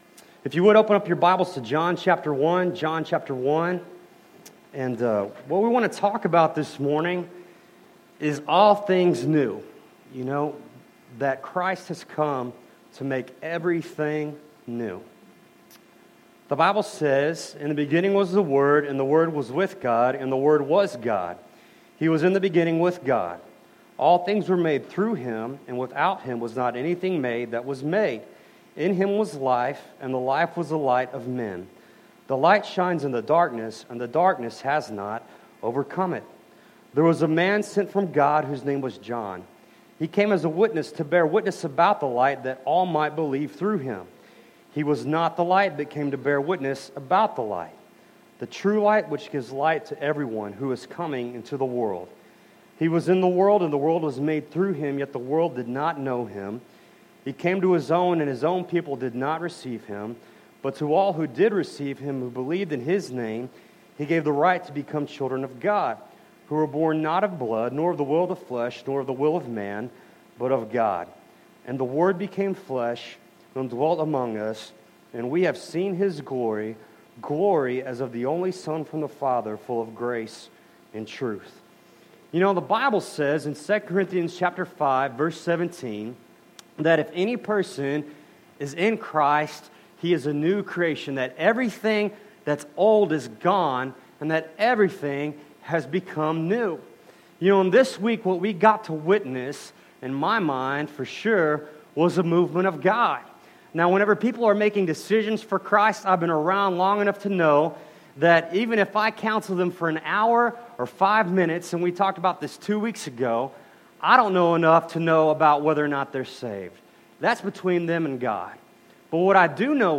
Passage: John 1: 1-14 Service Type: Sunday Morning